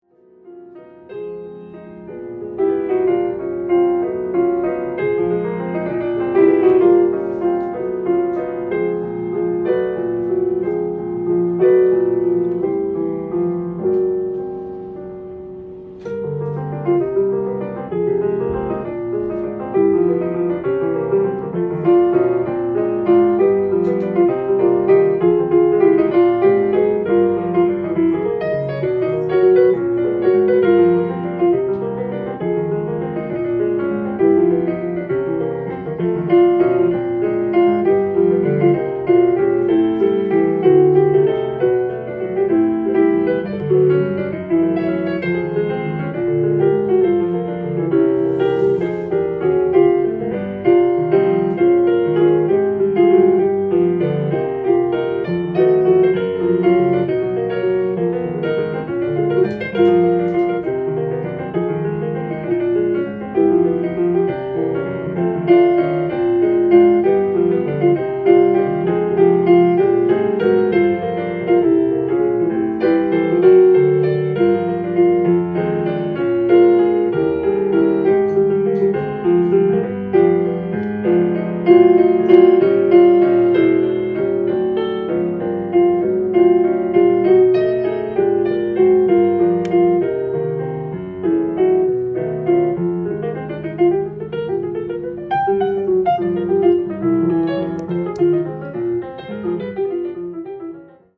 ジャンル：JAZZ-ALL
店頭で録音した音源の為、多少の外部音や音質の悪さはございますが、サンプルとしてご視聴ください。